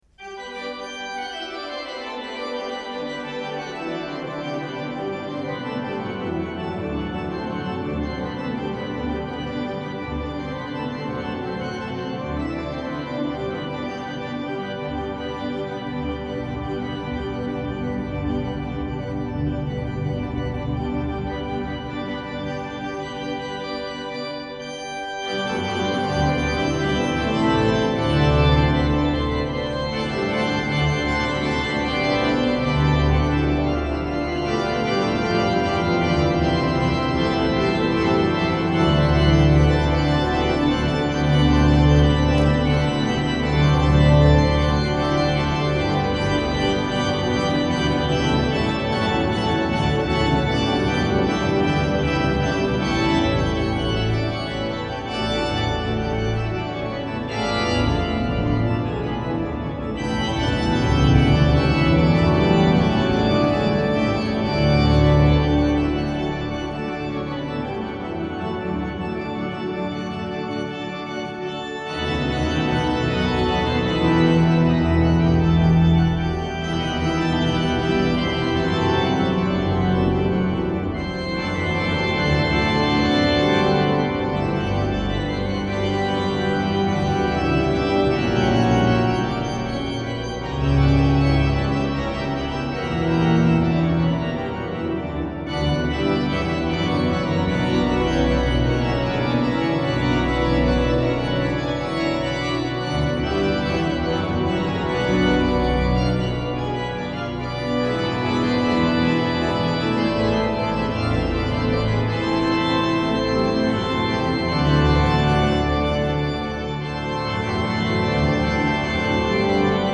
Concierto de Primavera a Luz de Vela
al Órgano Allen de la S.I. Catedral Metropolitana de Valladolid.
Grabación audio en estereo de gran calidad
High quality stereo audio recording
preludio+fuga-541.mp3